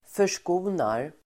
Uttal: [för_sk'o:nar]
f0366rskonar.mp3